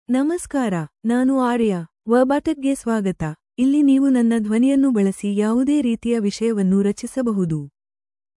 FemaleKannada (India)
AriaFemale Kannada AI voice
Aria is a female AI voice for Kannada (India).
Voice sample
Female
Aria delivers clear pronunciation with authentic India Kannada intonation, making your content sound professionally produced.